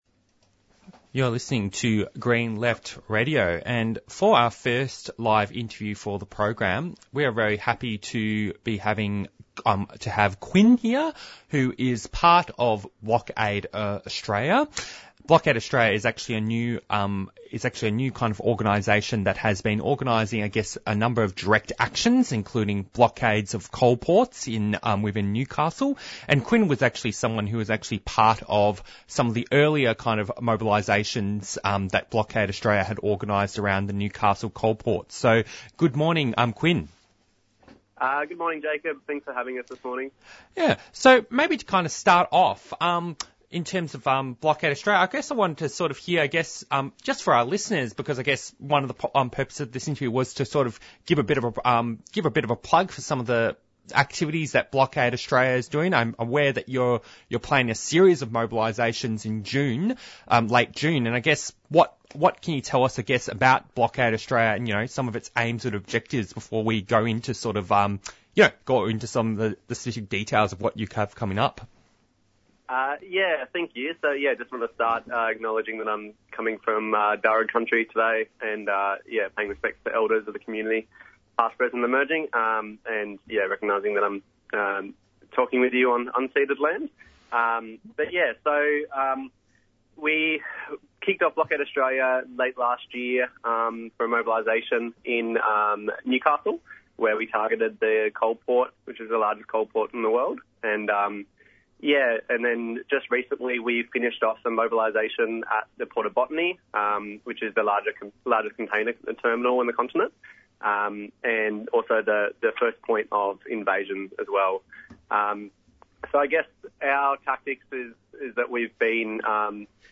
Newsreports
Interviews and Discussions